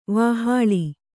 ♪ vāhāḷi